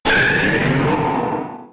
Cri de Méga-Florizarre K.O. dans Pokémon X et Y.